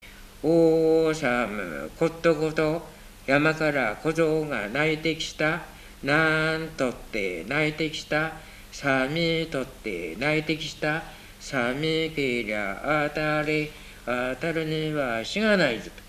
わらべ歌　冬 遊戯歌